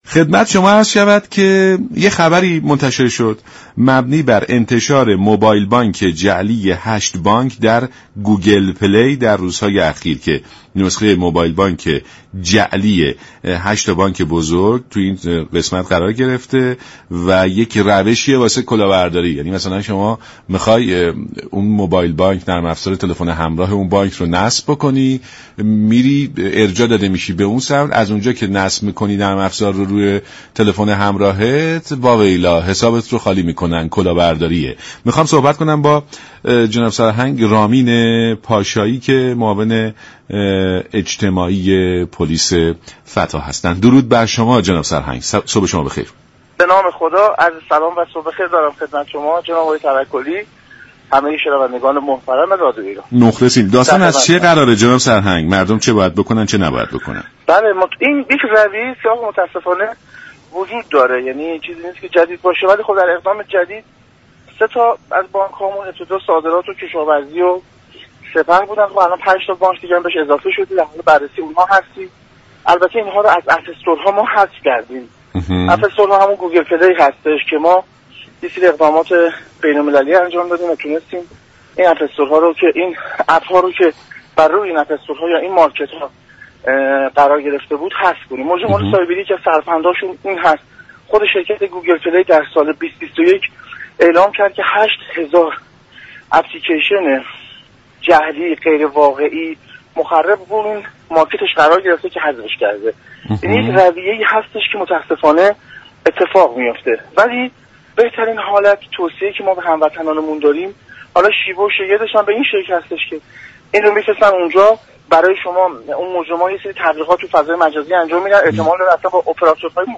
به گزارش شبكه رادیویی ایران، سرهنگ رامین پاشایی معاون اجتماعی پلیس فتا ناجا در برنامه «سلام صبح بخیر» رادیو ایران از انتشار موبایل بانك جعلی 8 بانك در گوگل پلی خبر داد و گفت: مجرمان سایبری در اقدامی جدید با طراحی و ارسال نرم افزارهای جعلی بانك‌های صادرات، كشاورزی و سپه و 5 بانك عامل دیگر به گوگل پلی زمینه را برای سوء استفاده و برداشت غیرمجاز از حساب بانكی افراد فراهم كرده اند.